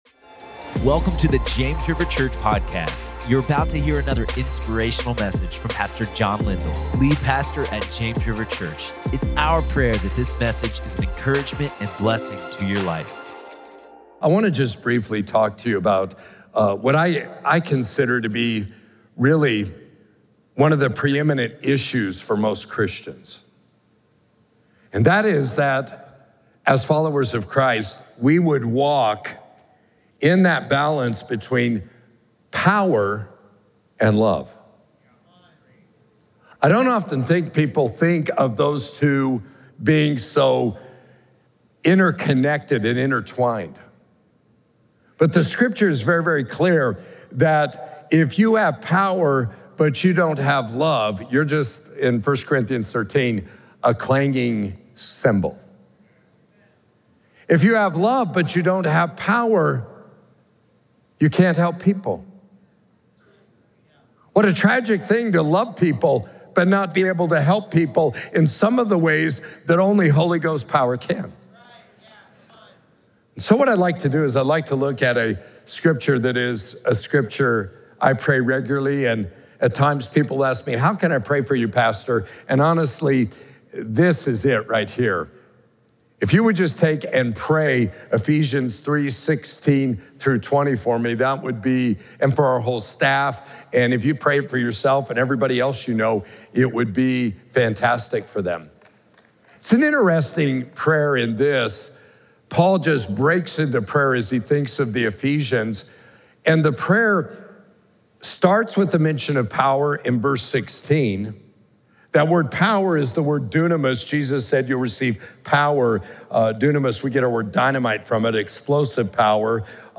Power & Love | Prayer Meeting | James River Church